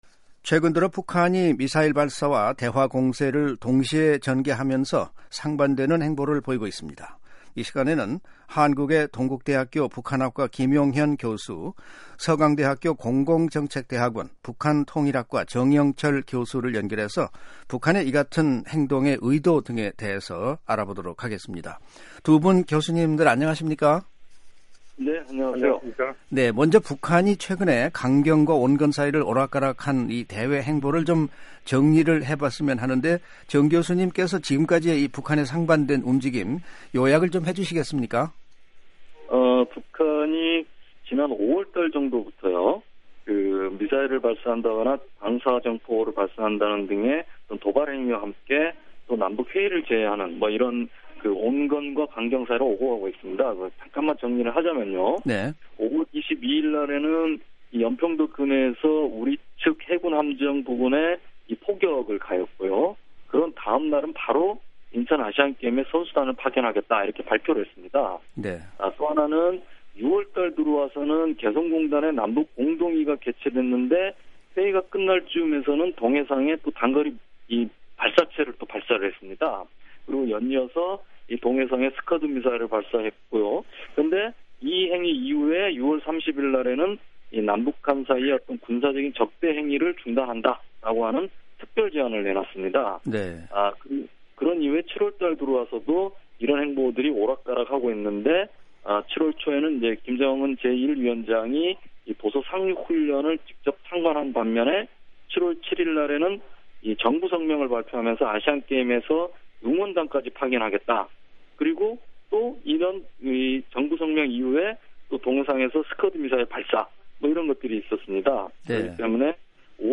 [인터뷰 오디오 듣기] 이중 행태 보이는 북한의 의도